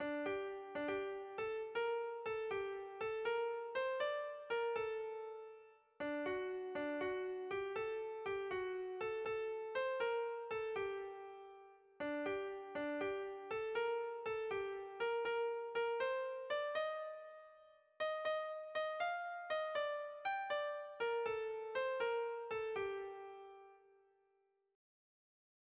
Bertso melodies - View details   To know more about this section
Sentimenduzkoa
A-A2-B-C